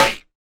Snare 019.wav